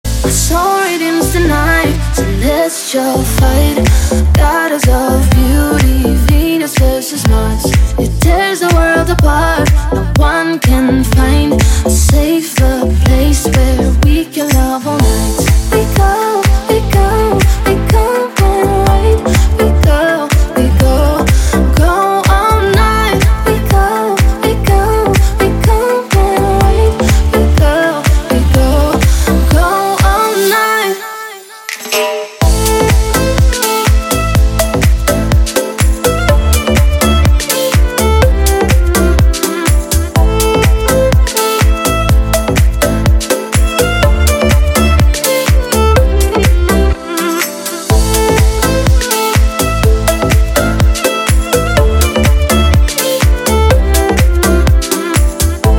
гитара
deep house
восточные мотивы
скрипка
nu disco
виолончель